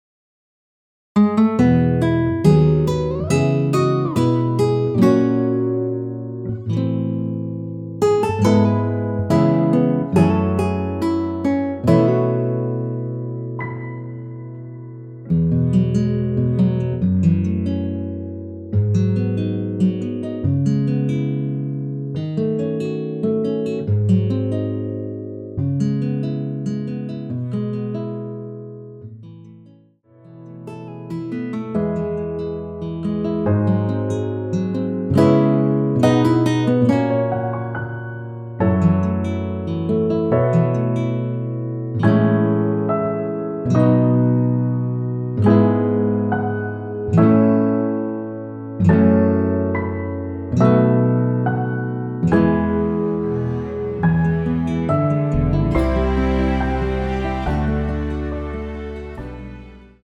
원키에서(-1)내린 (1절+후렴)MR입니다.
앞부분30초, 뒷부분30초씩 편집해서 올려 드리고 있습니다.
중간에 음이 끈어지고 다시 나오는 이유는